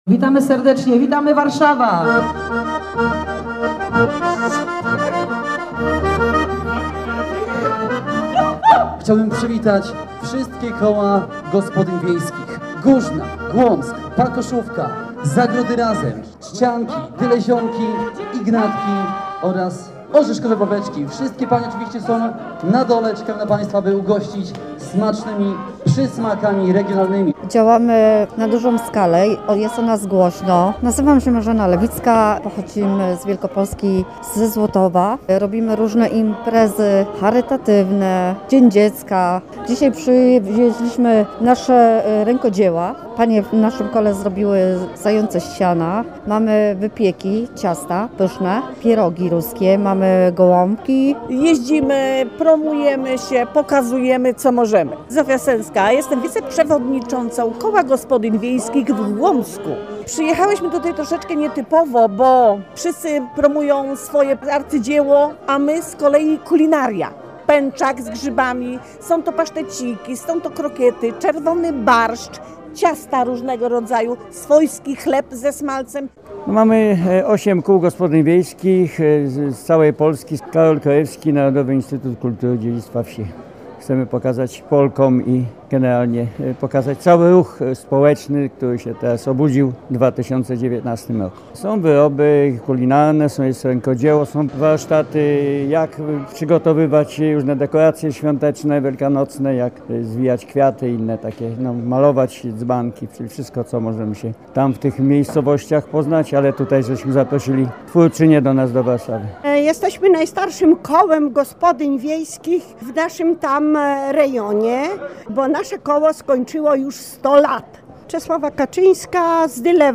W Narodowym Instytucie Kultury i Dziedzictwa Wsi w Warszawie zorganizowano jarmark z okazji Dnia Kobiet.
Dzięki temu trwa ten folklor, trwają te tradycje, zwyczaje, stroje, to co jest skarbem tego środowiska lokalnego – mówił o znaczeniu Kół Gospodyń Wiejskich Prezydent Andrzej Duda.
Kobiety z miast już pozazdrościły kobietom ze wsi i zaczęły zakładać Koła Gospodyń Miejskich – mówiła Pierwsza Dama, Agata Kornhauser Duda.